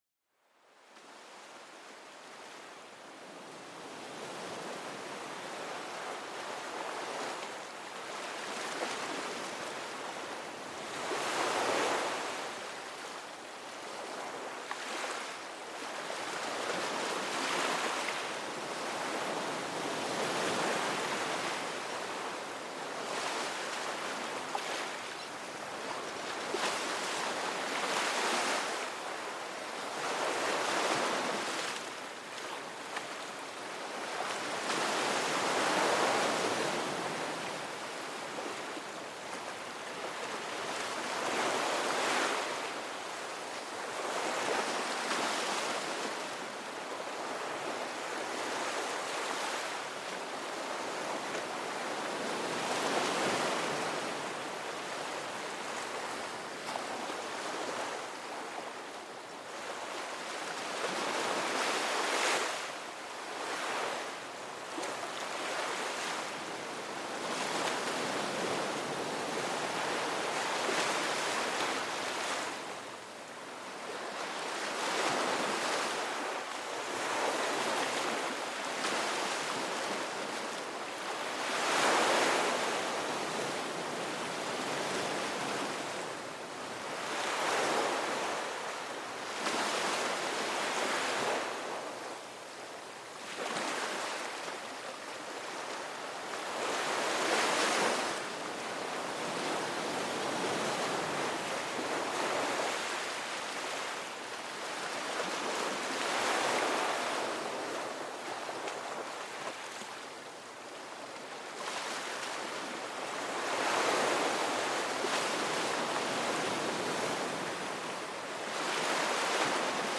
Sumérgete en el universo cautivador del sonido de las olas del océano, un viaje sonoro que acuna y apacigua. Déjate envolver por el ritmo regular y armonioso del agua, una sinfonía natural propicia para la relajación.